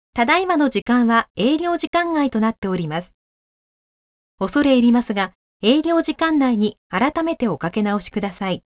【アナウンスサービス　メッセージ一覧】
■アナウンスサービス５